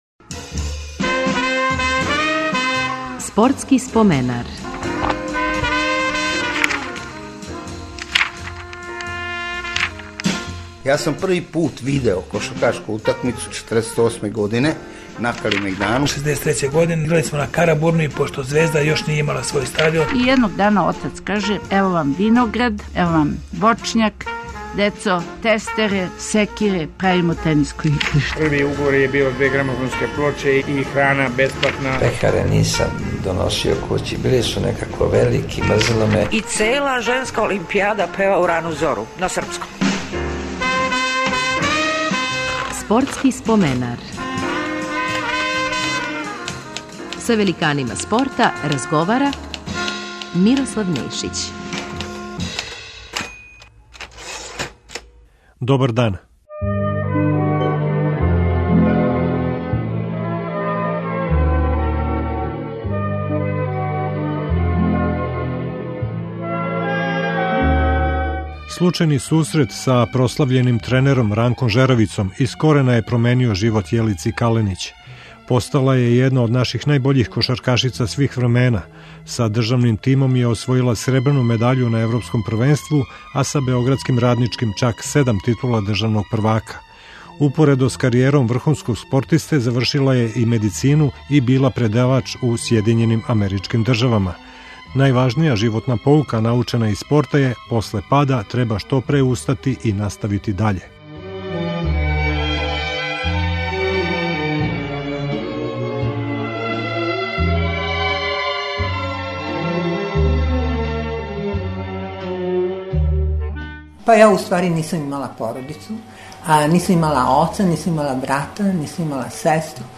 Гост емисије ће бити кошаркашица